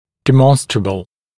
[dɪ’mɔnstrəbl][ди’монстрэбл]доказуемый; очевидный, наглядный